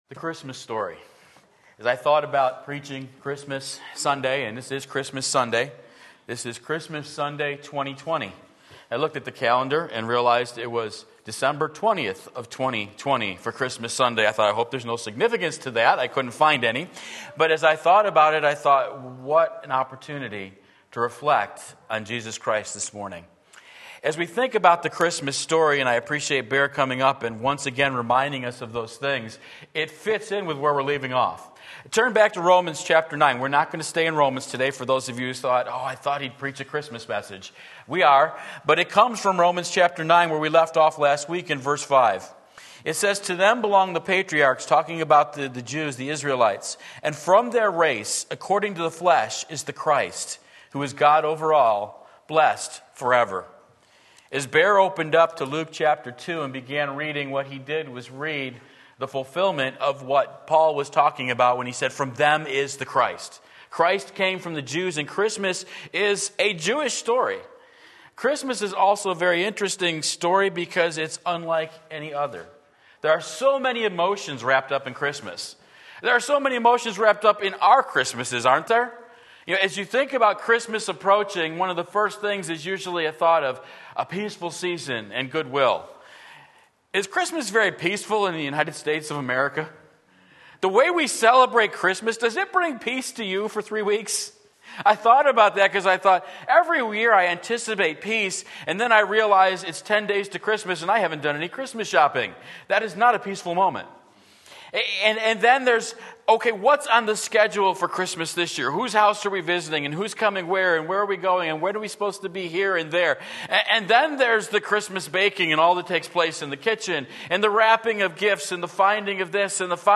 Sermon Link
When You're Filled with Fear Luke 2:1-20 Sunday Morning Service